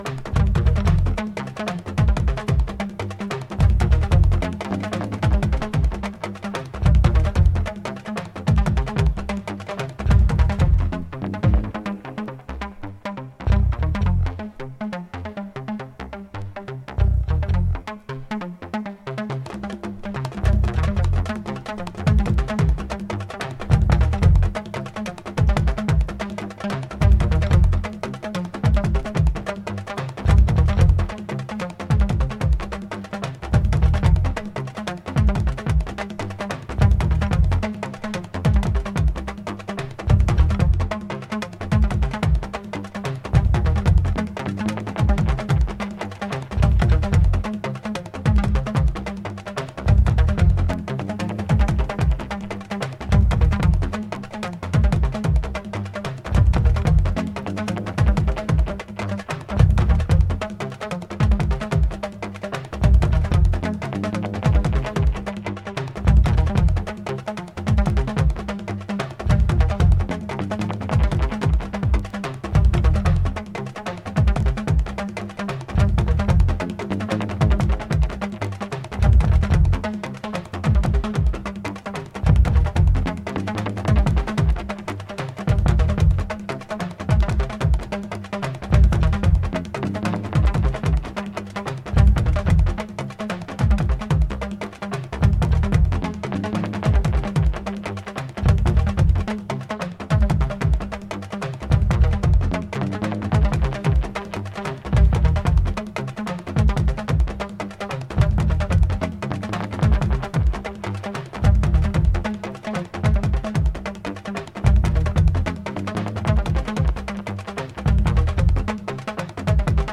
JUNGLE/BREAKBEAT